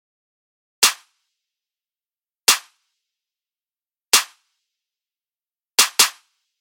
描述：陷阱和科幻的结合。沉重的打击和神秘感。
Tag: 145 bpm Trap Loops Percussion Loops 1.11 MB wav Key : D